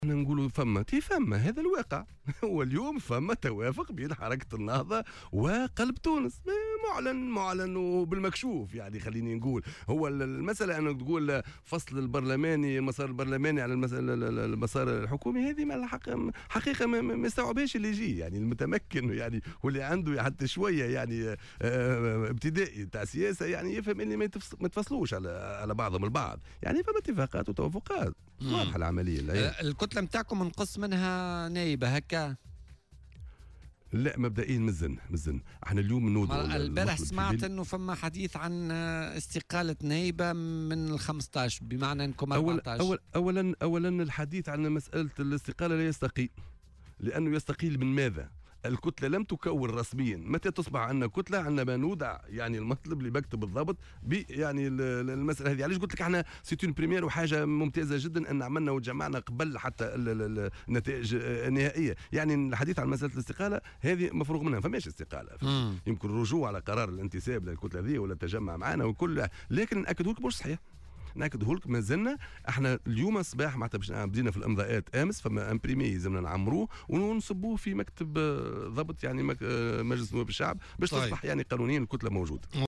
وأضاف في مداخلة له اليوم في برنامج "بوليتيكا" على "الجوهرة أف أم" أنه لا يمكن إخفاء ذلك وأن هناك اتفاقات وتوافقات بين الحزبين، وفق تعبيره.